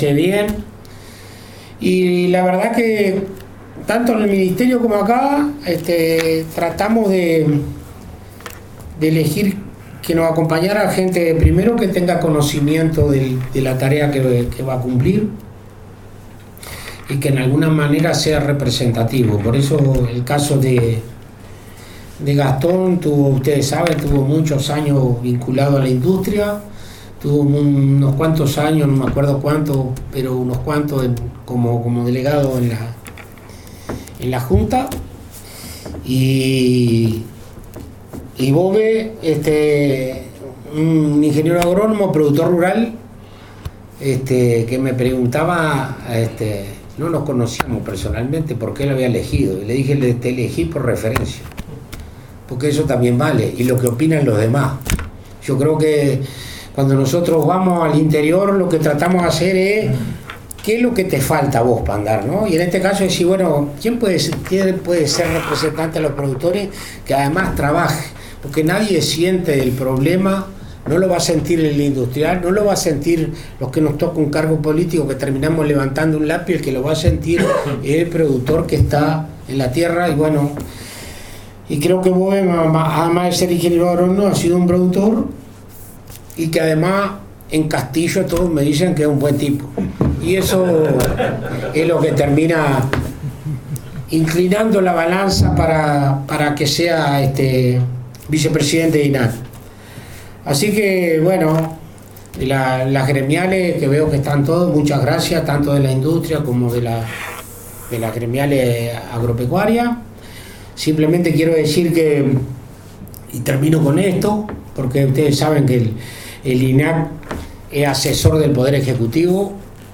Ante integrantes de Junta de INAC, colegas de otros Institutos y funcionarios, el ministro de Ganadería, Agricultura y Pesca Alfredo Fratti presentó al equipo que de aquí en más estará a cargo de la Junta como Presidente Gastón Scayola y Vicepresidente Leonardo Bove.